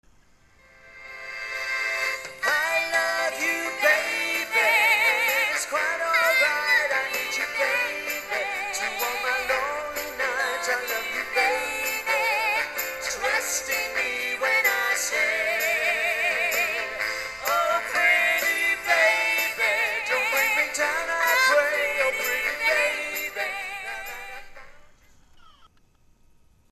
Wycombe Swan Theatre
Click here for sound clip featuring Bonnie and Limahl singing a specially arranged version of "I Can't Take My Eyes Off You" and "Something Stupid".
OrchestraBonnieLimahl.mp3